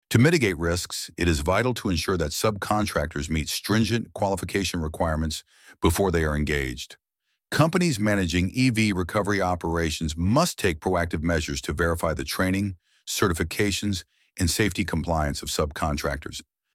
ElevenLabs_Topic_1.4.1.mp3